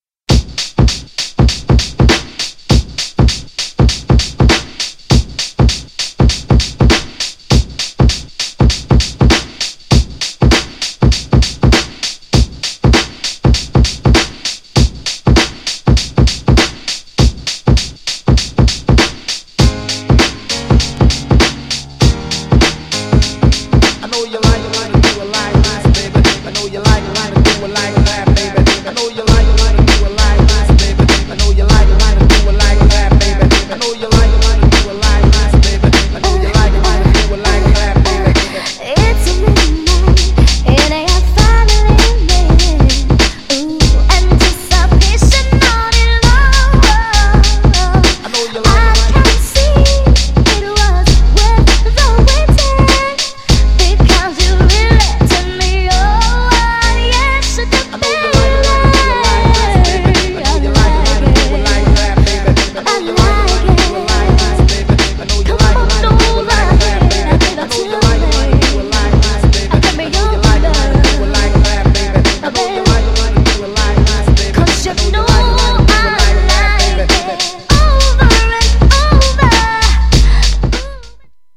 女の子受けも良いキャッチーで踊れるR&B!!
GENRE R&B
BPM 91〜95BPM
90s_HIP_HOP_SOUL
SMOOTH_R&B # 女性VOCAL_R&B